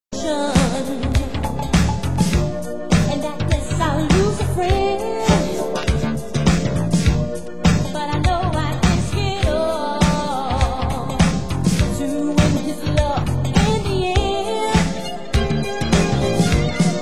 Genre: R & B